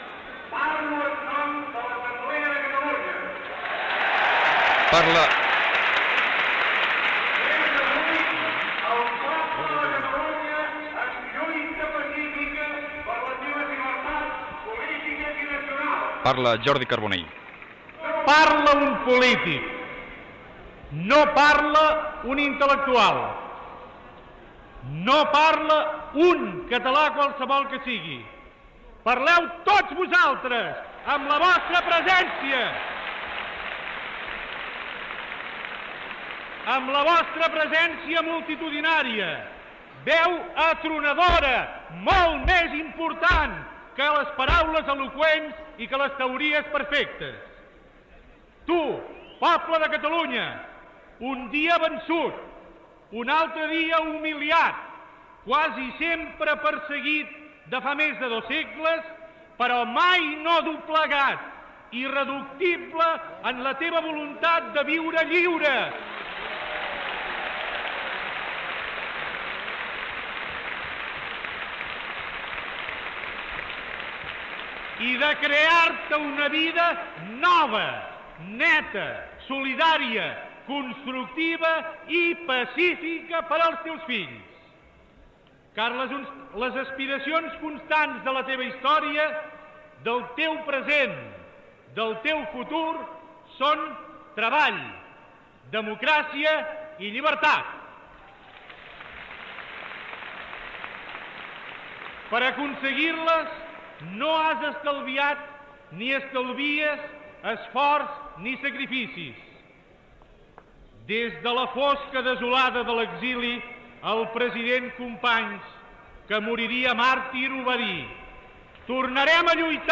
Discurs del polític i filòleg
a la Plaça de Catalunya de Sant Boi de Llobregat a la Diada Nacional de Catalunya
Va ser la primera Diada multitudinària, amb una assistència de 80.000 persones a Sant Boi de Llobregat.